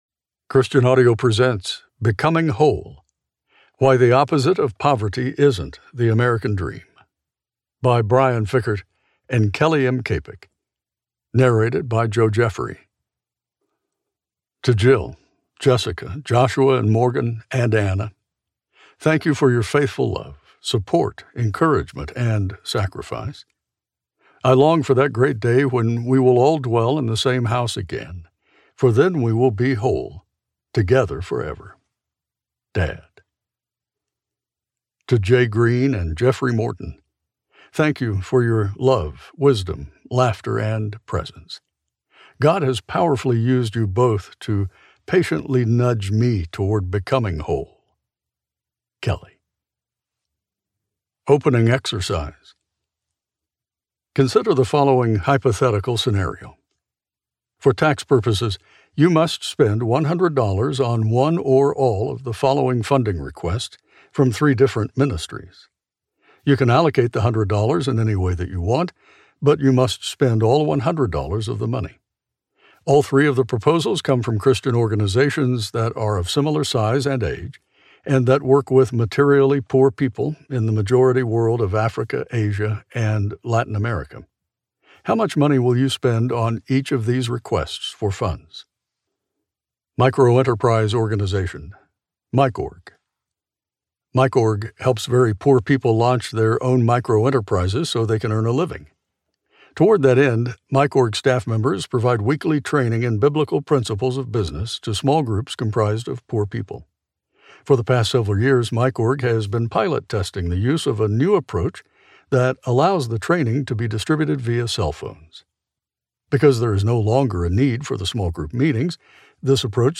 Becoming Whole Audiobook